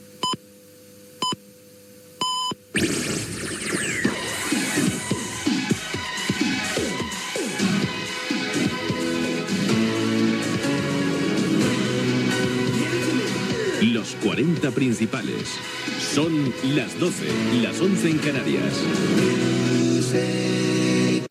Indcatiu horari